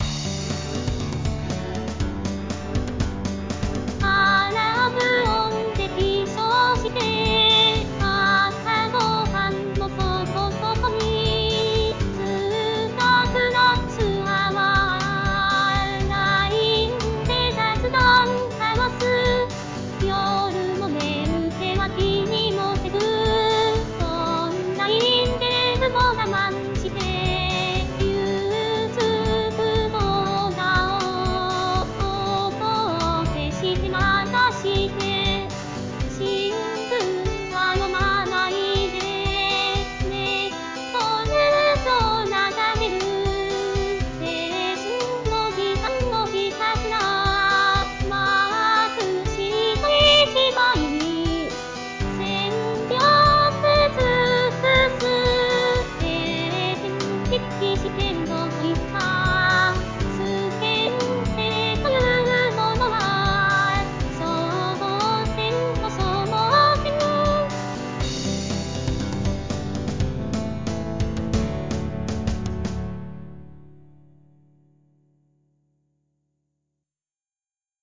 日本語歌詞から作曲し、伴奏つき合成音声で歌います。